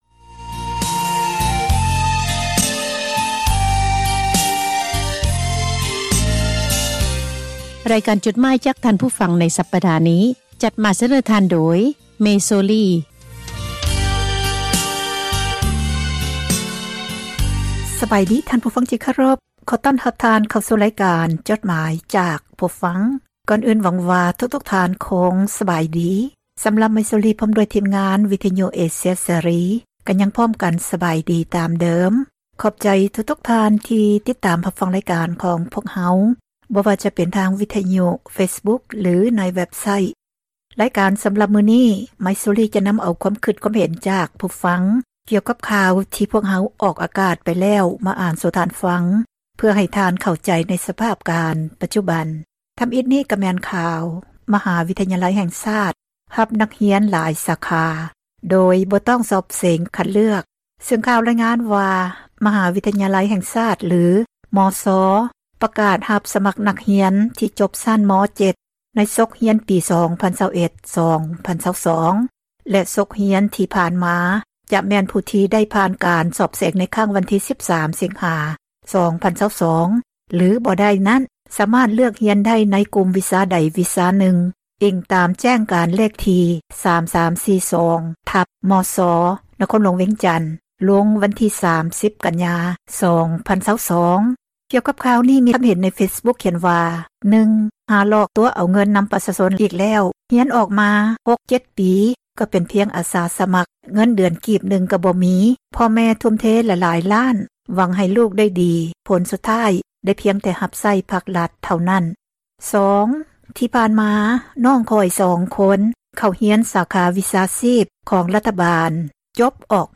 ລຳສີພັນດອນ